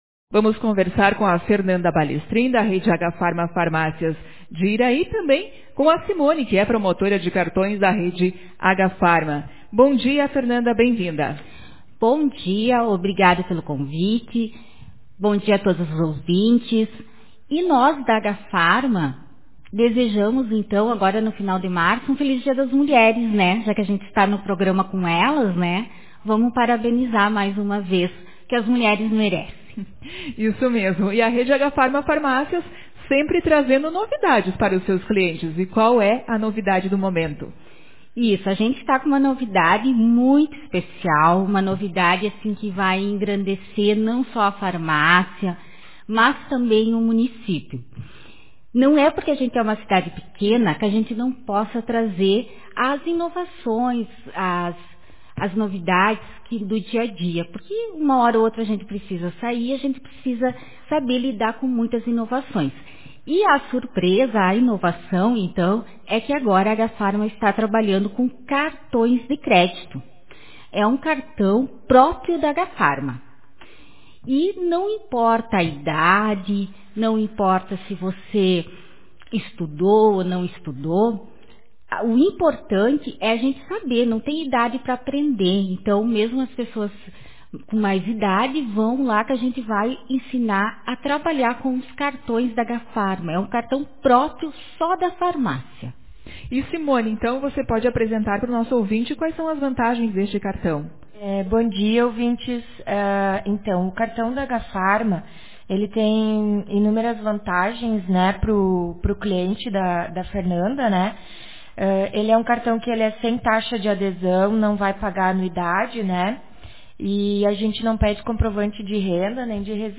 Na ocasião foram detalhadas as vantagens do cartão de crédito Agafarma, novidade que já está disponível na Farmácia Agafarma de Iraí. Confira a entrevista